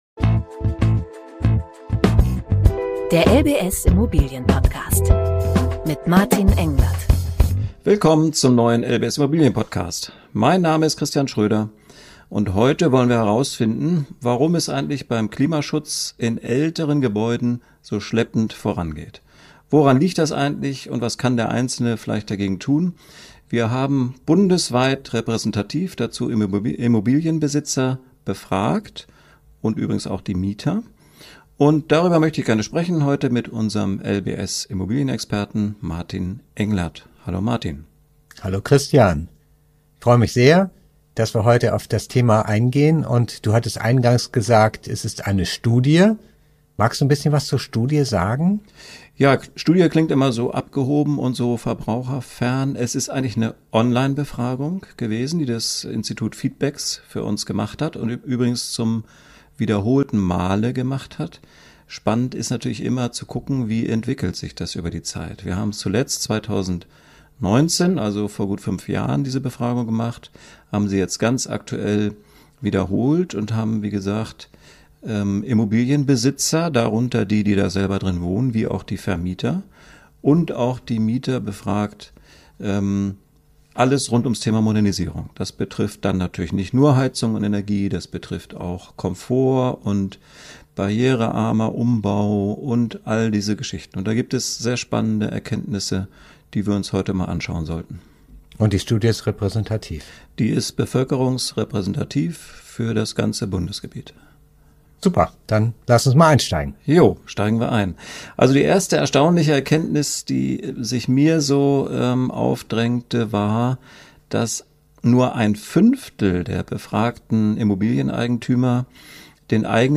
In dieser Folge unterhalten sich unsere Immobilienexperten